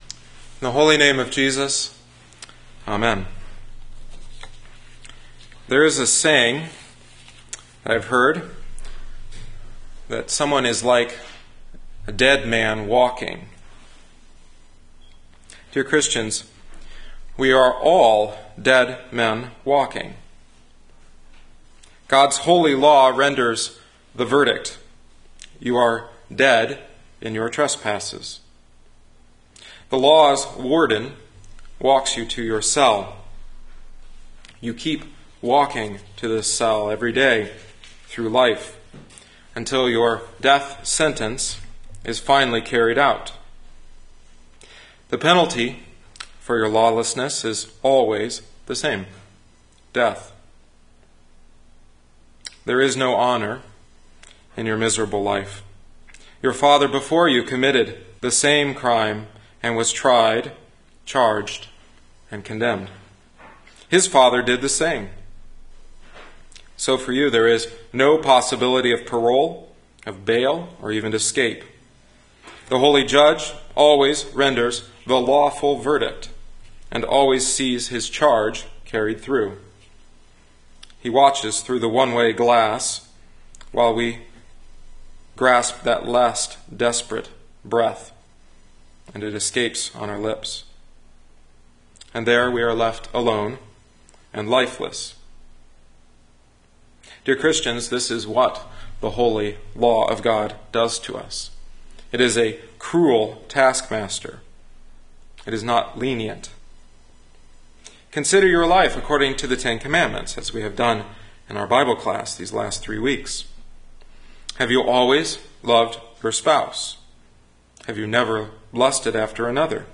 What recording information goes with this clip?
September 2010 Sixteenth Sunday after Trinity Luke 7:11-17 “Dead Men Walking”